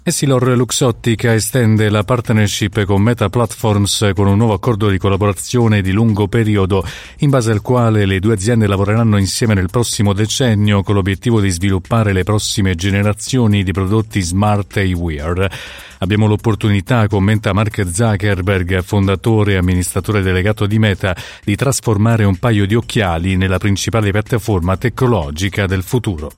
GIORNALE RADIO NAZIONALE EDIZIONE DELLE 13.00